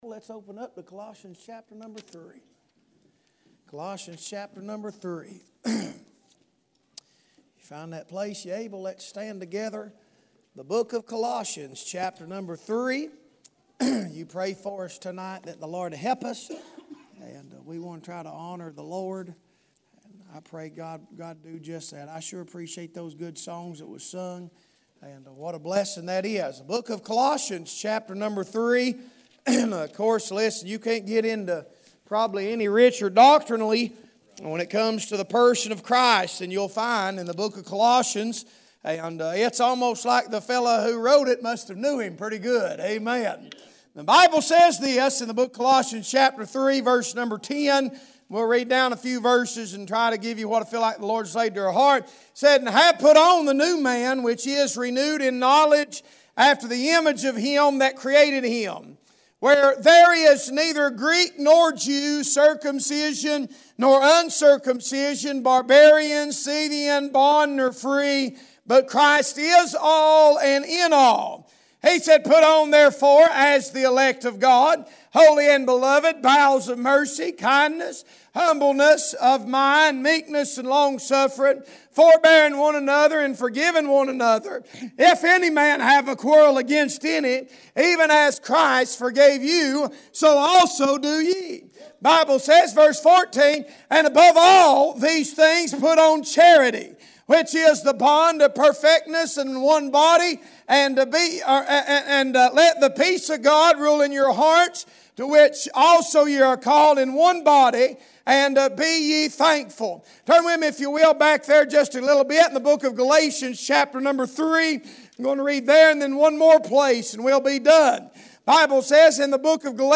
Passage: Colossians 3: 10-15; Galatians 3: 27-29; Romans 13: 11-14 Service Type: Wednesday Evening